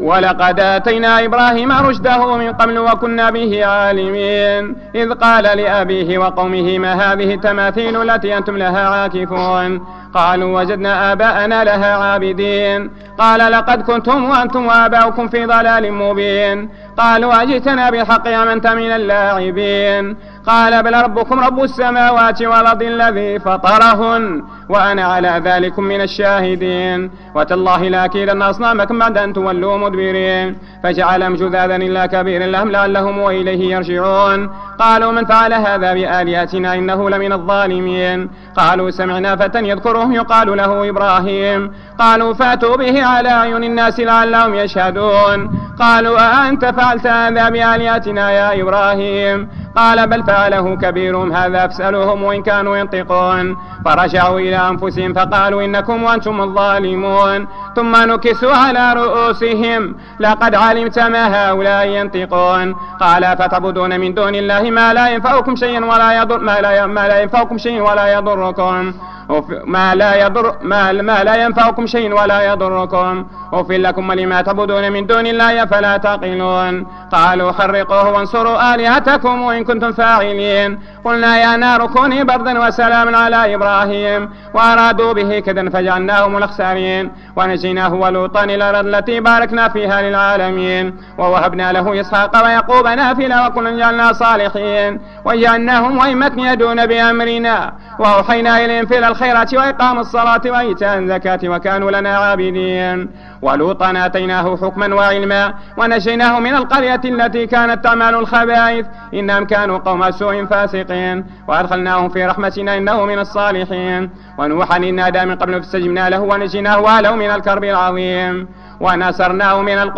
صلاة التراويح رقم 06 بمسجد ابى بكر الصديق فقارة الزوى
حصري بموقعنا فلا تتردد للتحميل قراءات نادرة اضغط الرابط اسفله